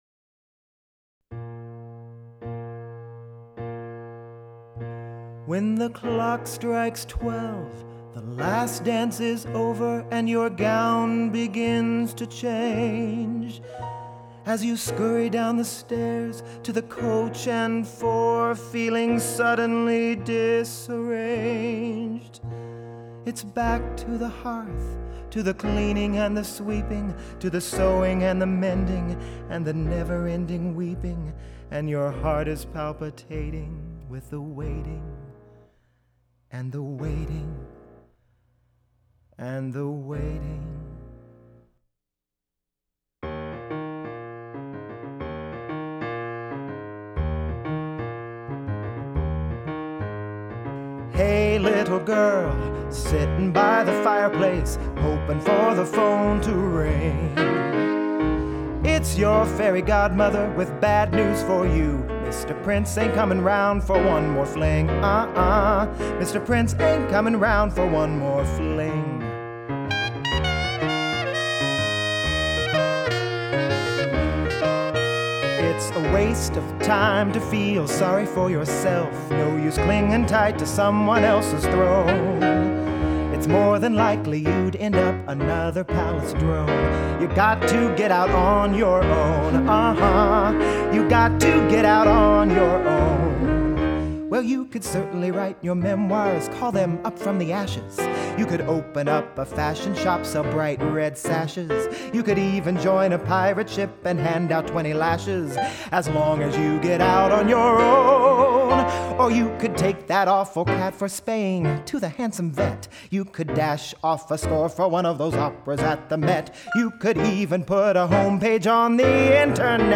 PLAY THE ORIGINAL DEMO RECORDING
Song style/genre: Up tempo, jazzy comic song
Key(s): D minor to A major